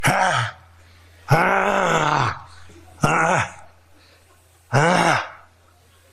Donald Trump Growl